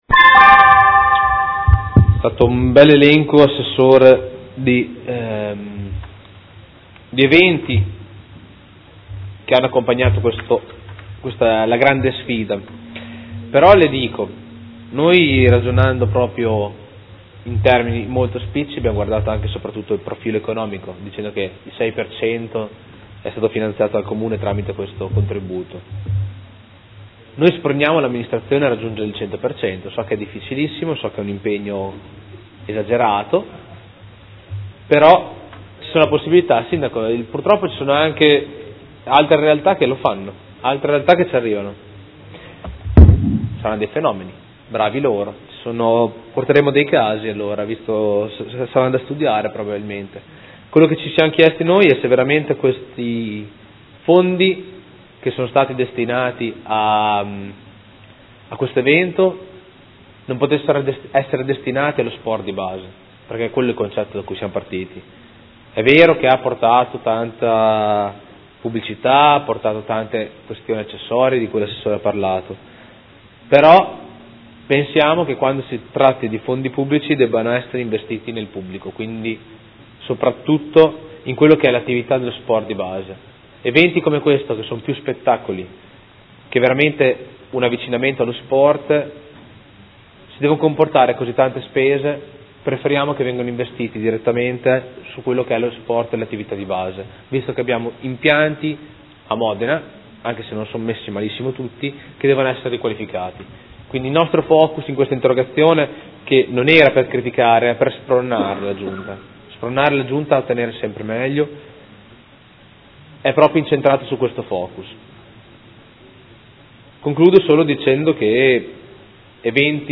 Seduta del 18/02/2016 Replica a risposta Assessore. Interrogazione del Gruppo Consiliare Movimento 5 Stelle avente per oggetto: La Grande Sfida